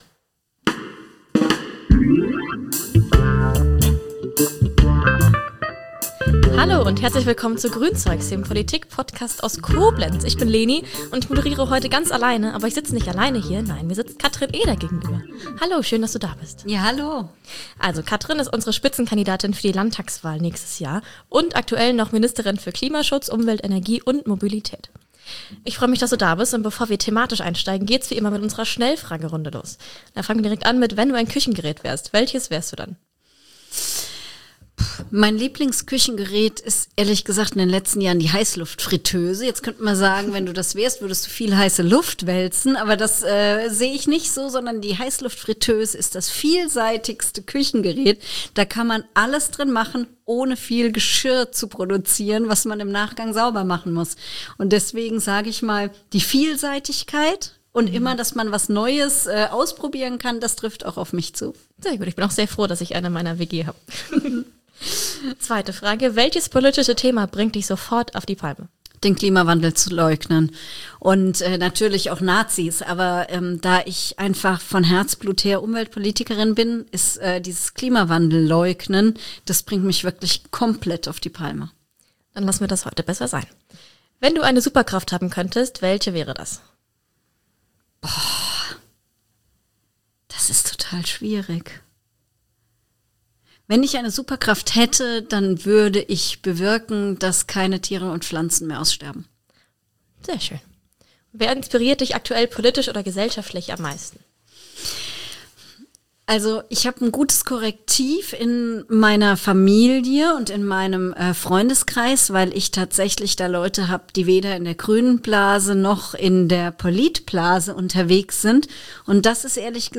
Ist unsere Gesellschaft im Rückwärtsgang? Im Gespräch mit Katrin Eder